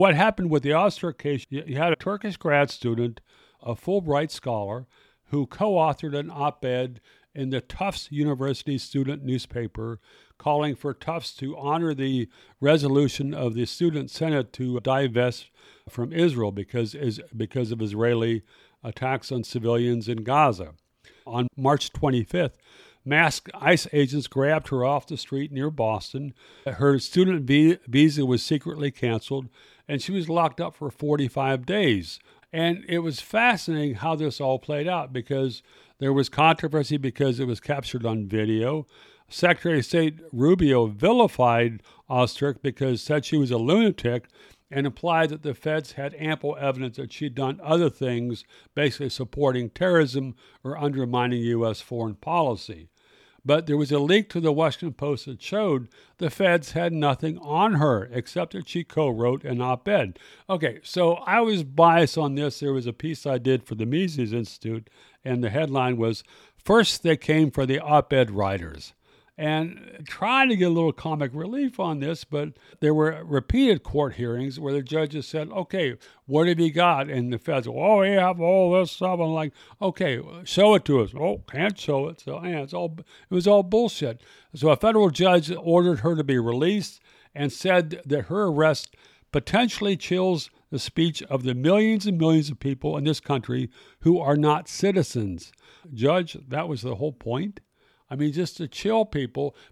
You can listen to the rowdy hour long discussion on Spotify here or on Player FM or on Podbean.